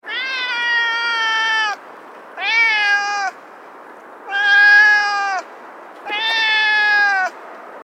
دانلود آهنگ گربه مادر از افکت صوتی انسان و موجودات زنده
دانلود صدای گربه مادر از ساعد نیوز با لینک مستقیم و کیفیت بالا
جلوه های صوتی